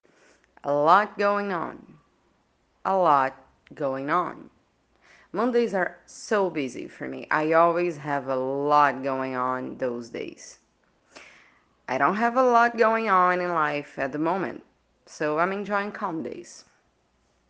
Attention to pronunciation 🗣: